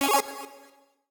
UIClick_Retro Delay 04.wav